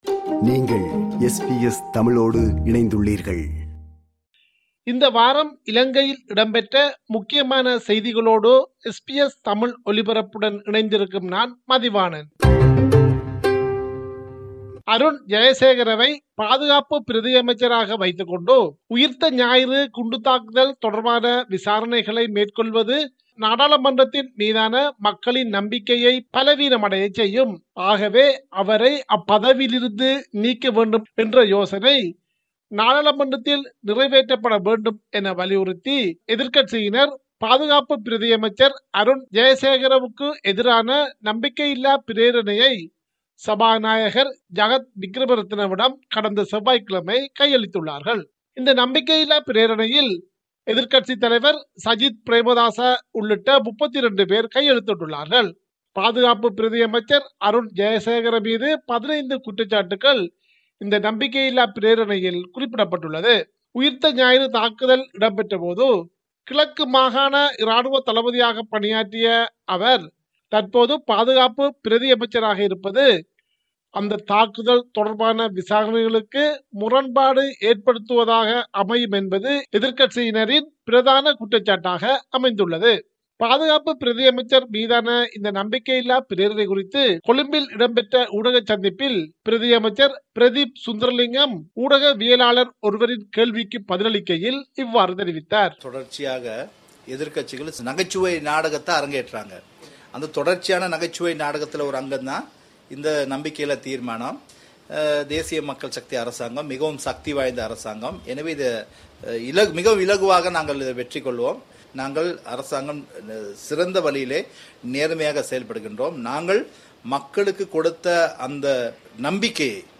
இலங்கையின் இந்த வார முக்கிய செய்திகள்
Top news from Sri Lanka this week To hear more podcasts from SBS Tamil, subscribe to our podcast collection.